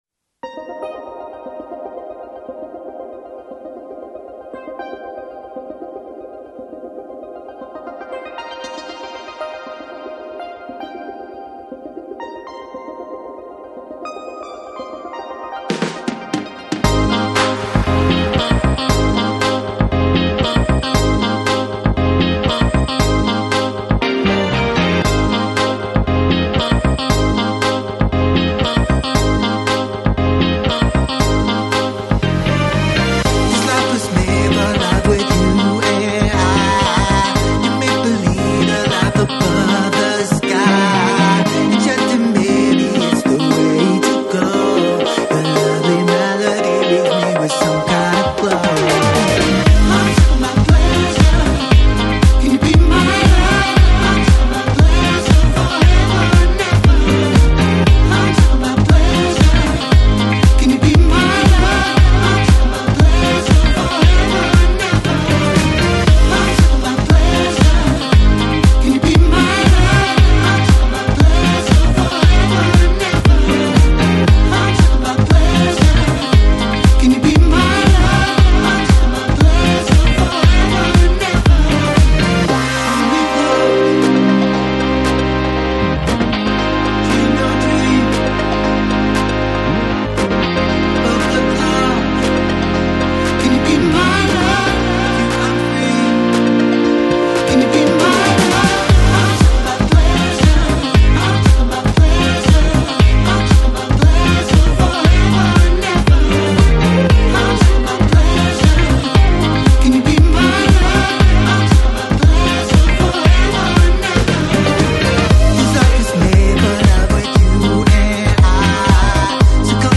Electronic, Synthpop, Indie, Electro Страна